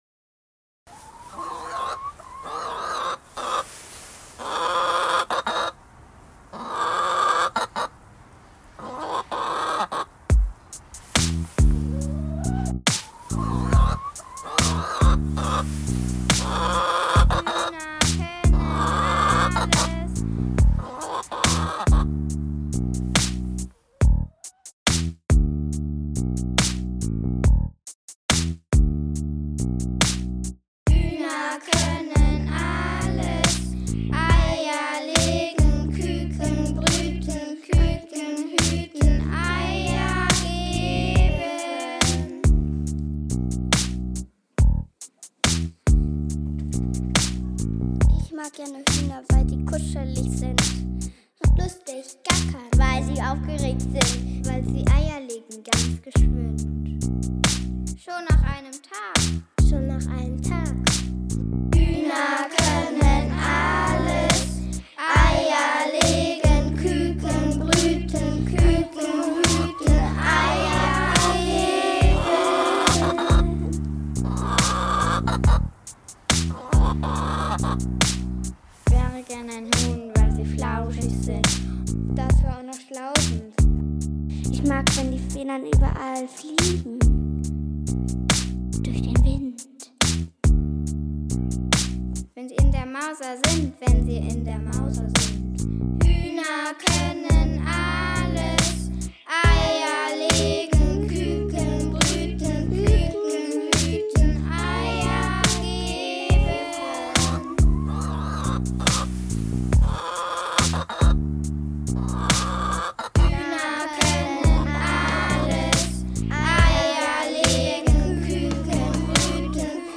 So sind in kleinen Gruppen vier tolle Raps entstanden.
21-05-Hühnerrap-Giraffen.mp3